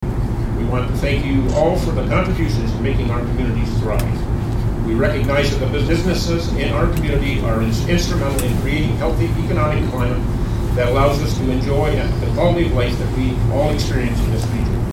At Belleville’s Ramada Hotel, they welcomed the Municipality of Brighton to the event, which focused on thanking the business community, and talked about the changing workplace.
Quinte West Deputy Mayor Jim Alyea thanked the business leaders that were in the room for their service to the community.